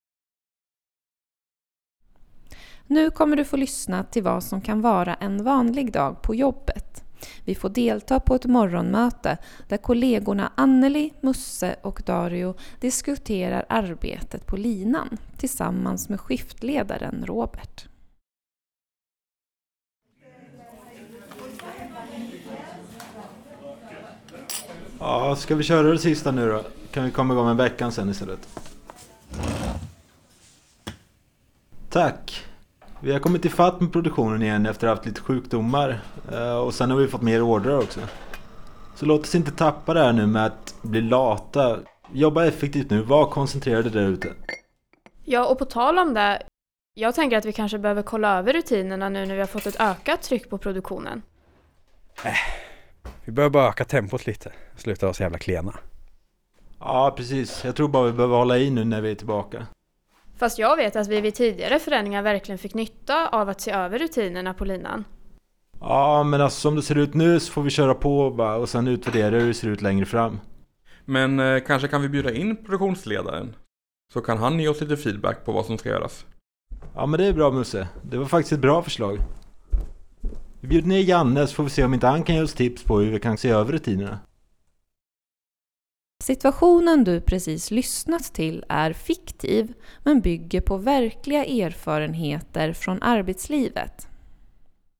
Övningen fokuserar på fem olika scenarion i radioteaterform som bygger på erfarenheter från svenska arbetsplatser.